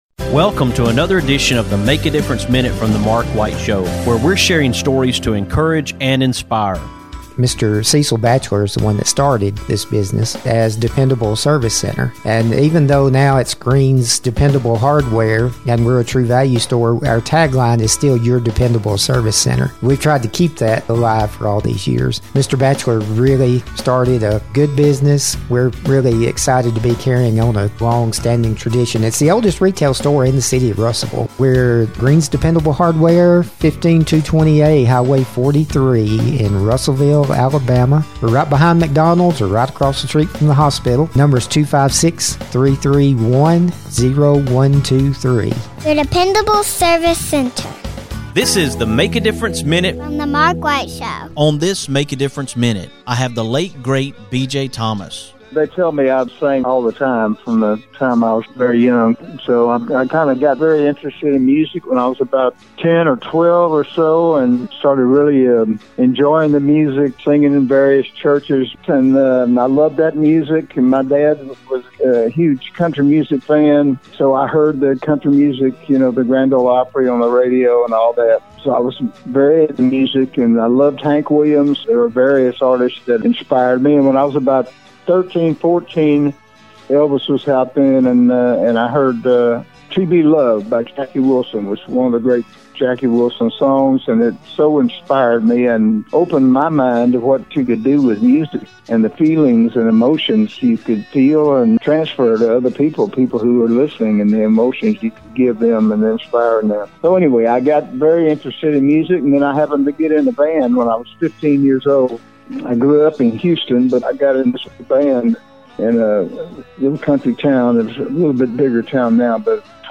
In an effort to support this album, on today’s Make A Difference Minute, I’m sharing some of my interview with BJ where he shared about his love of music as a young person and the early beginnings of his music career.